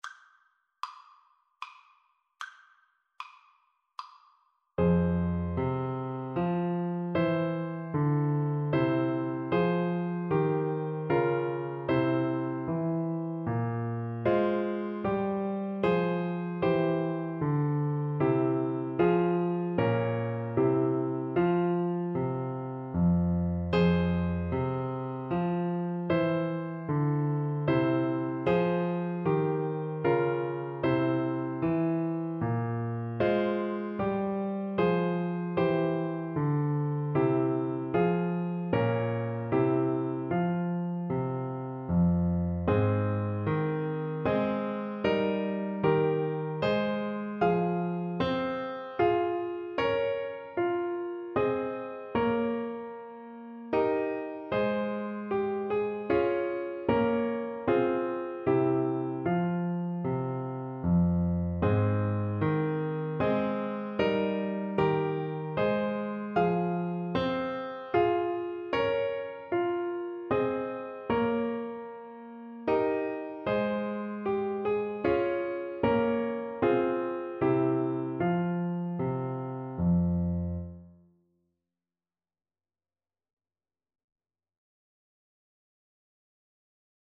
Play (or use space bar on your keyboard) Pause Music Playalong - Piano Accompaniment Playalong Band Accompaniment not yet available transpose reset tempo print settings full screen
F minor (Sounding Pitch) G minor (Trumpet in Bb) (View more F minor Music for Trumpet )
Moderato
3/4 (View more 3/4 Music)
Classical (View more Classical Trumpet Music)